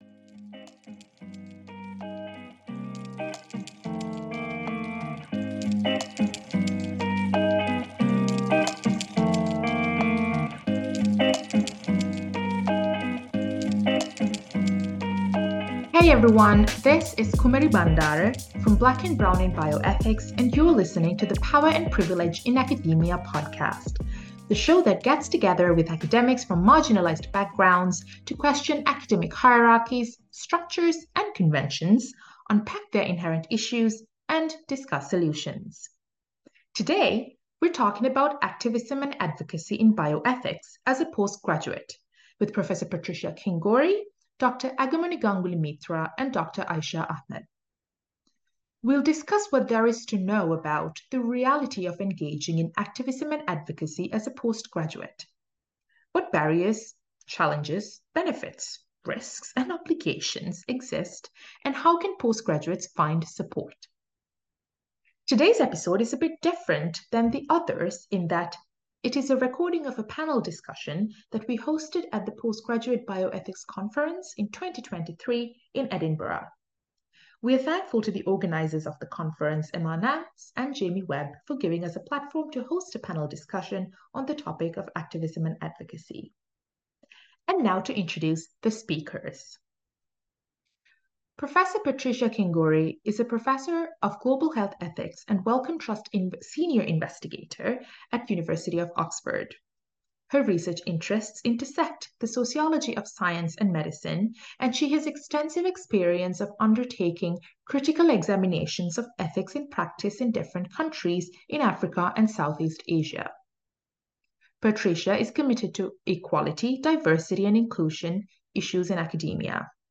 This episode is a little different from our usual format—it's a recording of a live panel discussion, shared here with the speakers' prior consent. The event took place at the 2023 Postgraduate Bioethics Conference, held in June at the University of Edinburgh.